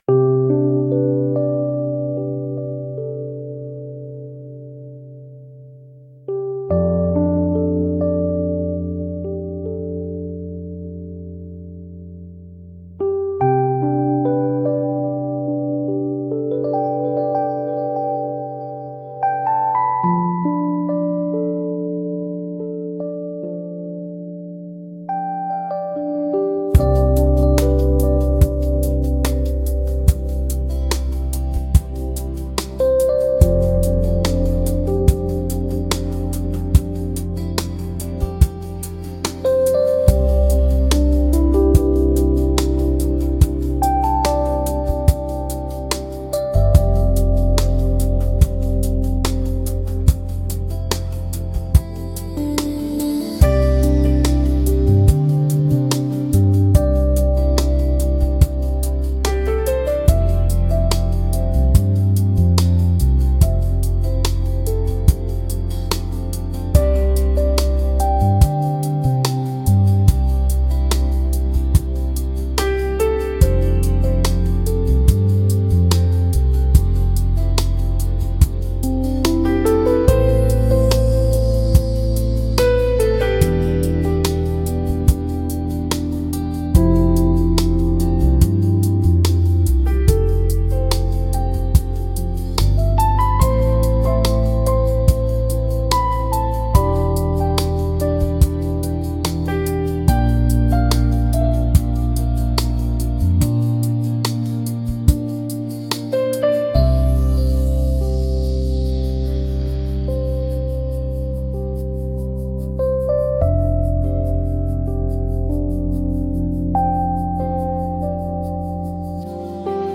1. Инструментальная и фоновая музыка без вокала
myagkij-fon-dlya-podkasta.mp3